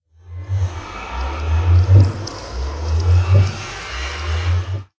portal.ogg